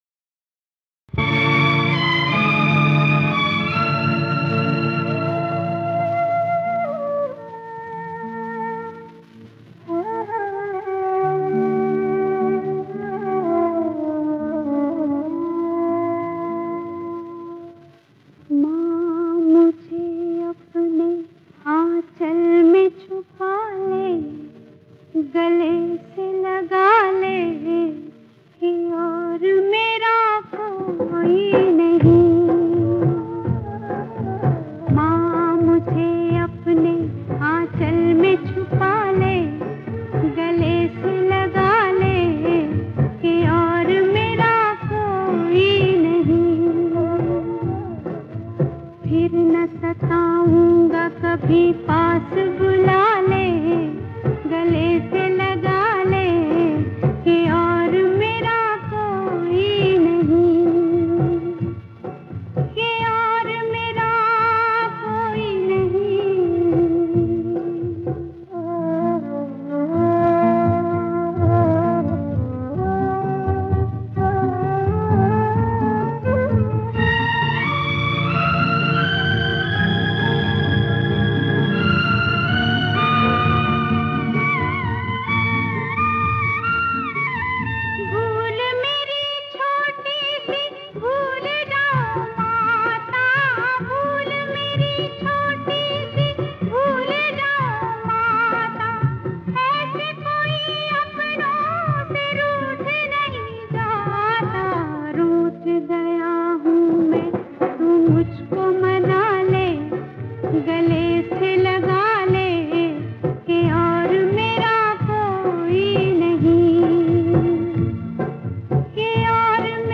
Hindi Movie